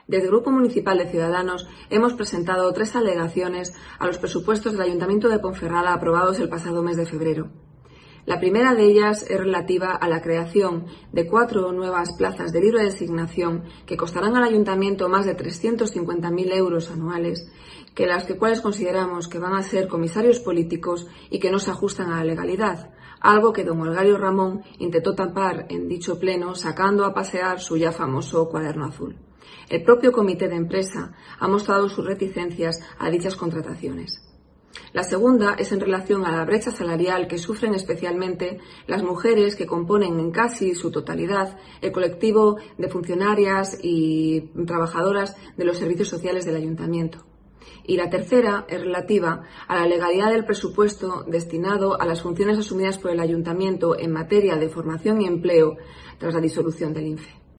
La formación naranja considera que cuatro contrataciones "no se ajustan a legalidad". Escucha aquí a la portavoz de Cs en la capital berciana, Ruth Morales